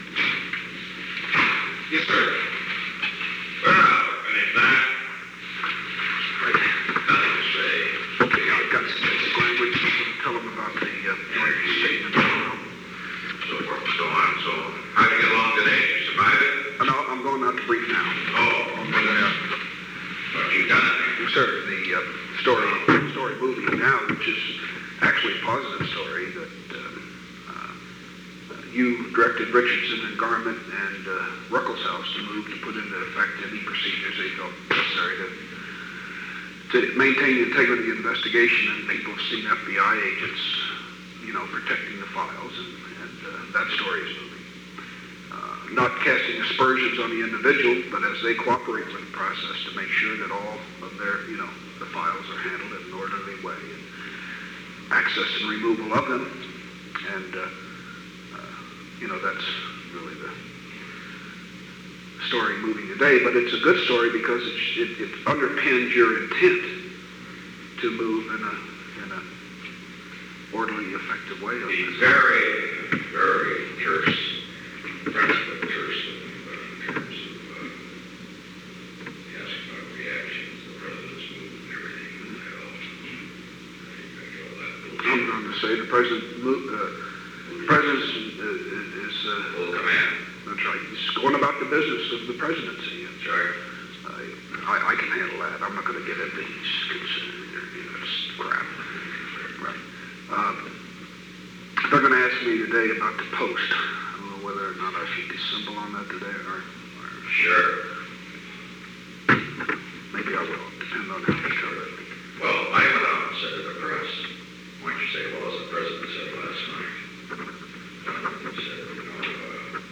Conversation No. 908-15 Date: May 1, 1973 Time: 12:27 pm - 12:39 pm Location: Oval Office The President met with Ronald L. Ziegler.
Secret White House Tapes